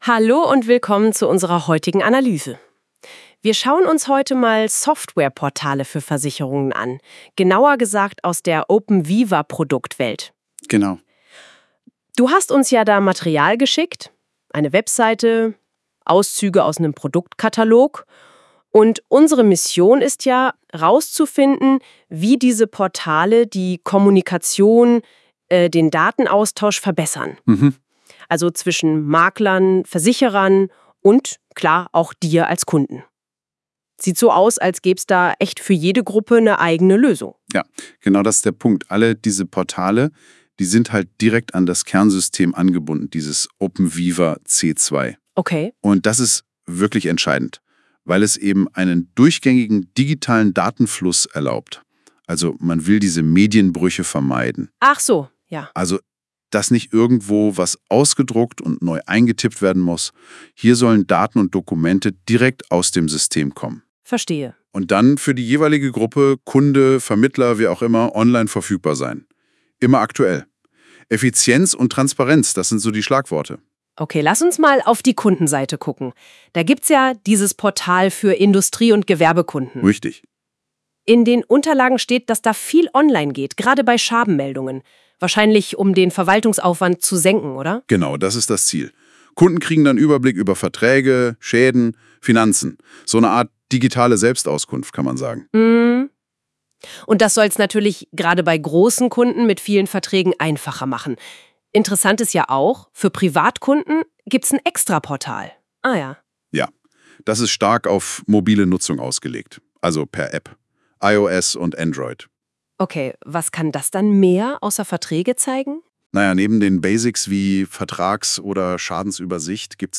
Praxisnah und verständlich erklärt uns die künstliche Intelligenz von Google NotebookLM die Modulgruppen von openVIVA c2.